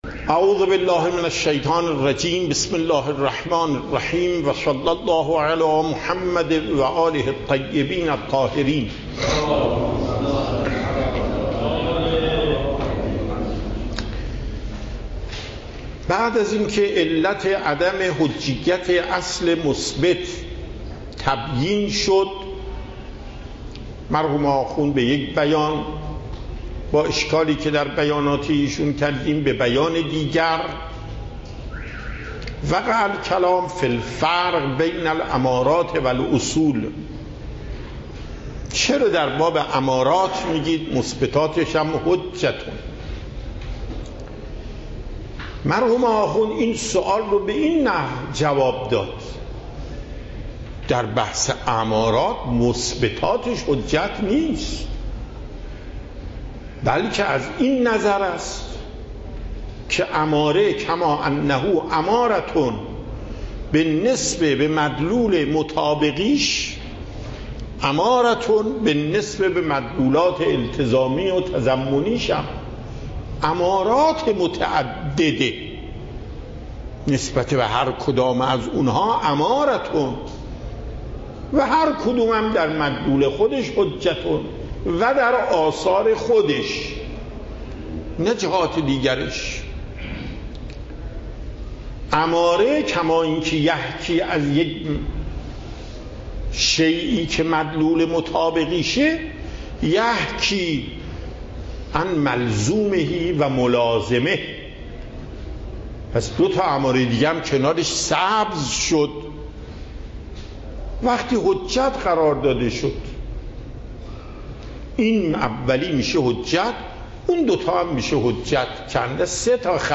صوت درس
درس اصول آیت الله محقق داماد